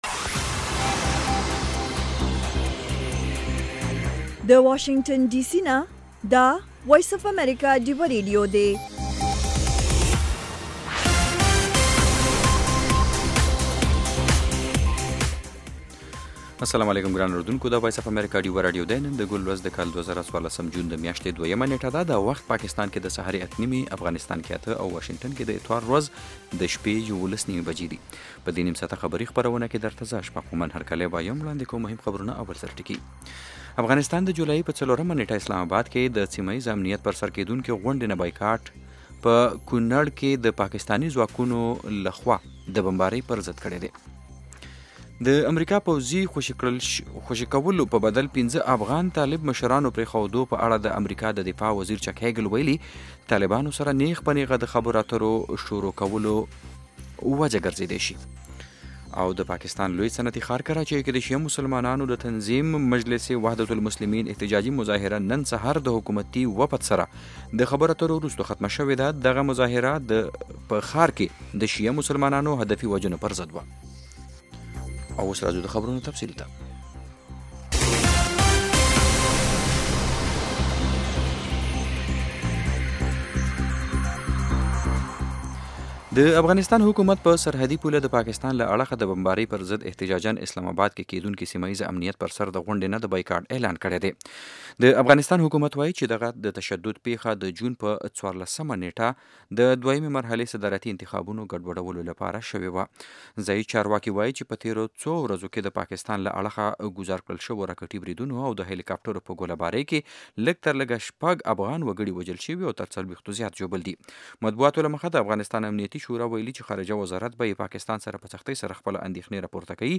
خبرونه - 0330
د وی او اې ډيوه راډيو سهرنې خبرونه چالان کړئ اؤ د ورځې دمهمو تازه خبرونو سرليکونه واورئ.